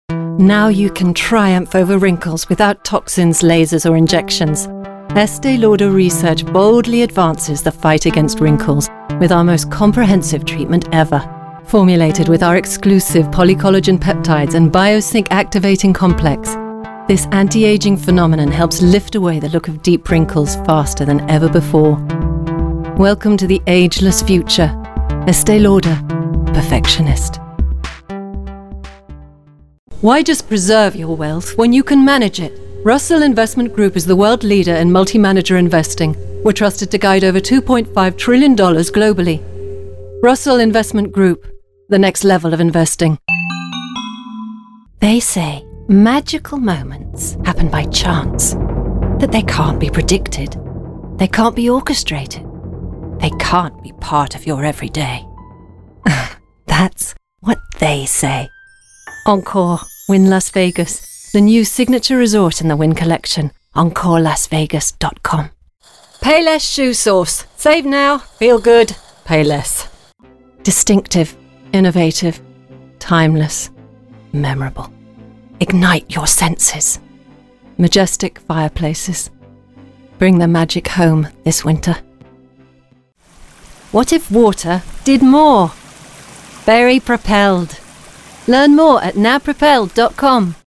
Commercial (British accent) - EN